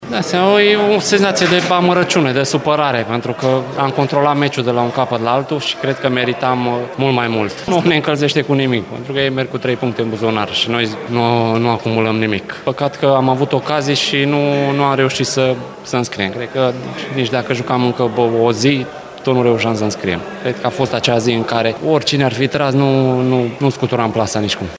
Atacantul UTA-ei, Ioan Hora, e supărat după această înfrângere, considerând că echipa sa merita mult mai mult: